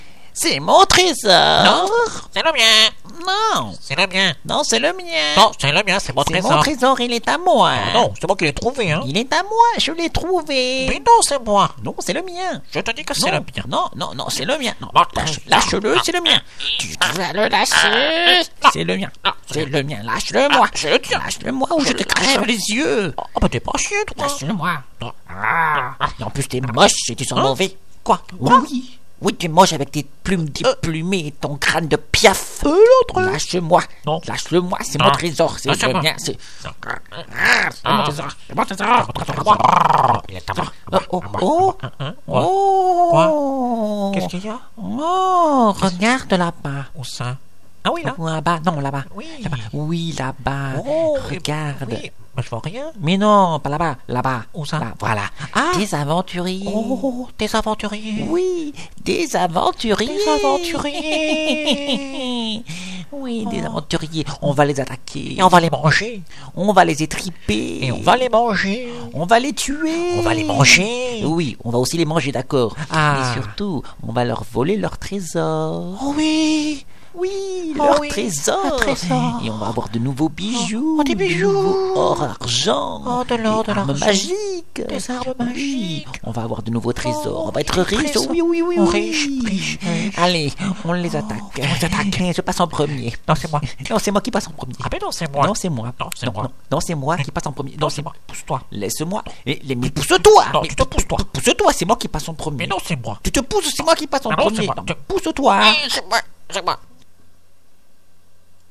Ces hydrides de vieilles femmes et d'oiseaux passent leur temps à se chamailler entre-elles
harpie.mp3